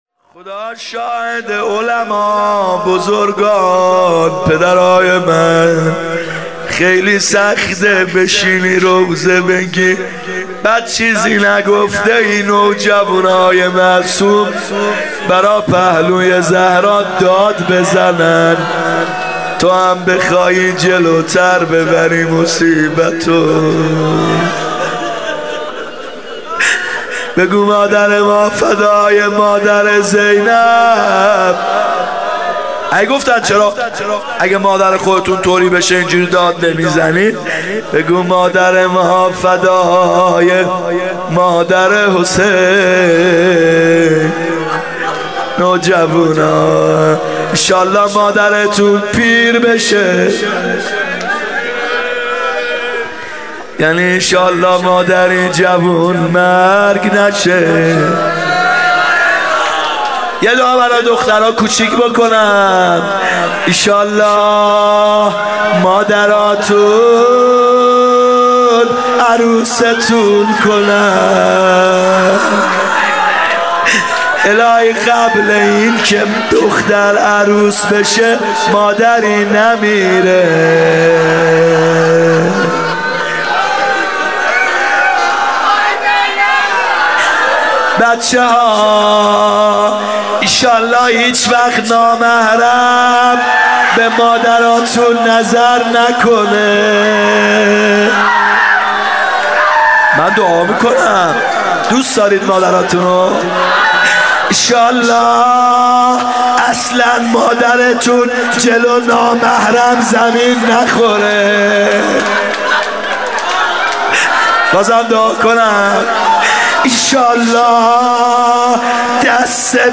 فاطمیه 94 روضه
فاطمیه هیات یامهدی عج